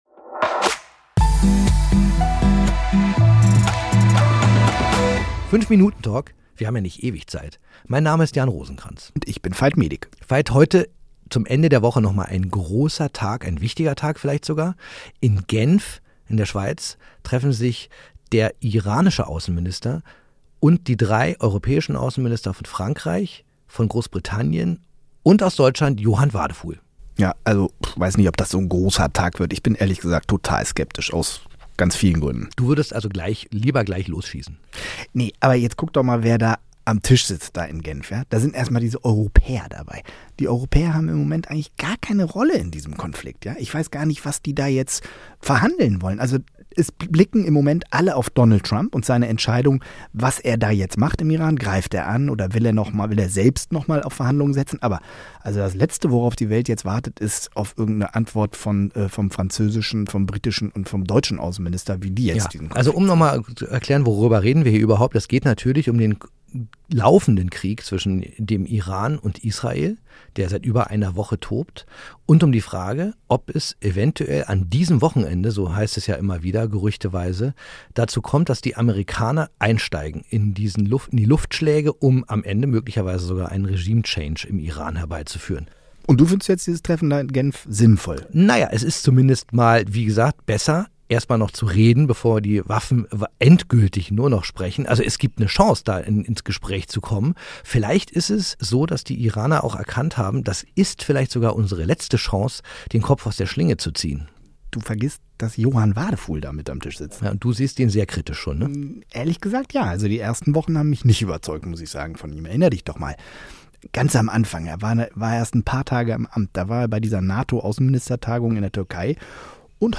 diskutieren über die heikle Mission.